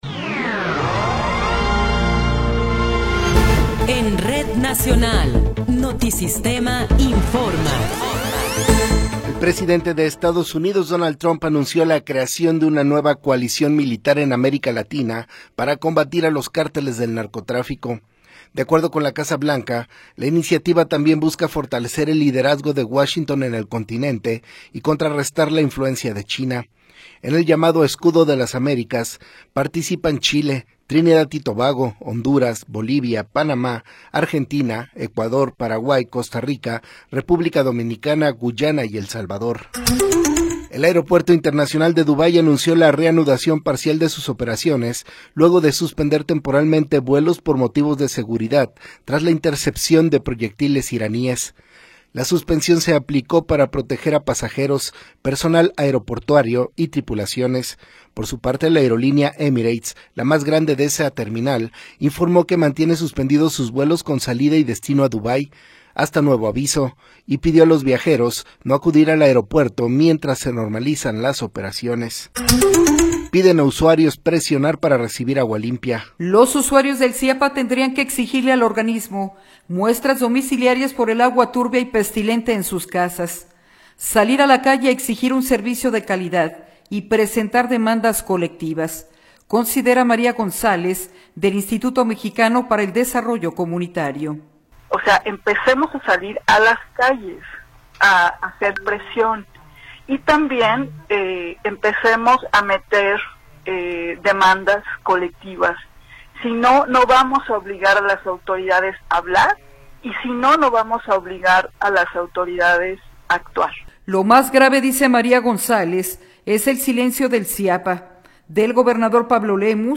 Noticiero 10 hrs. – 7 de Marzo de 2026